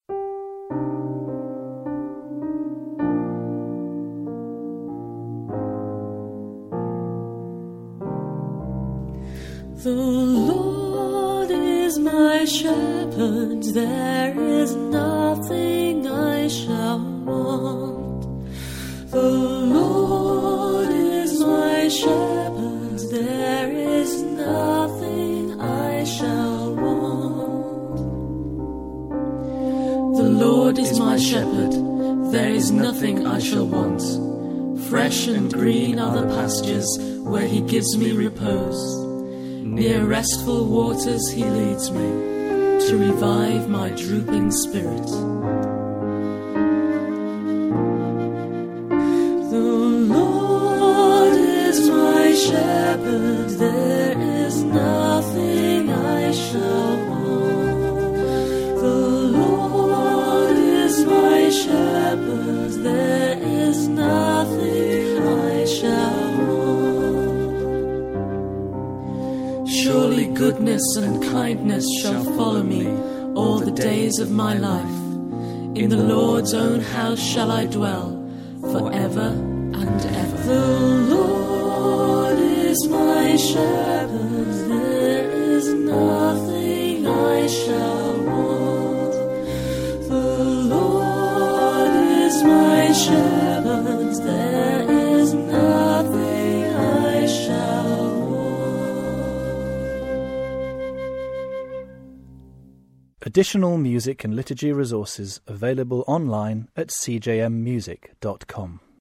Responsorial Psalm for the 4th Sunday of Easter